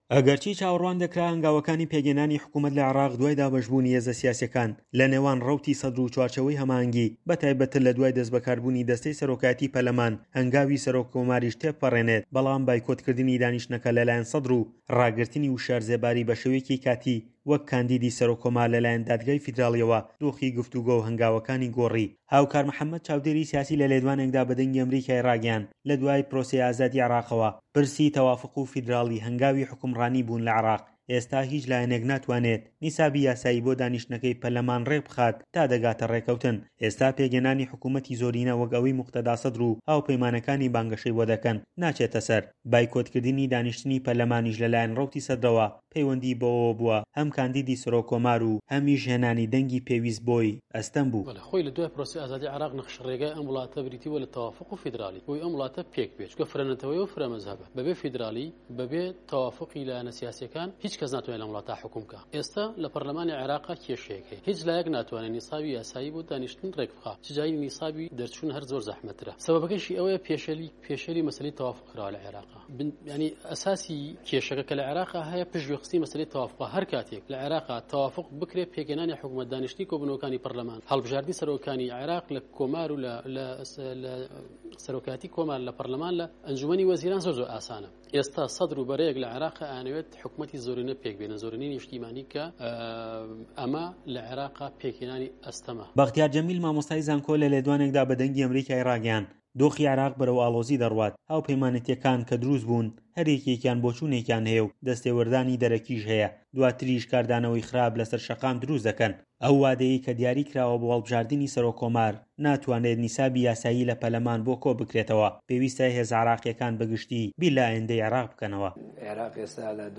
دەقی ڕاپۆرتی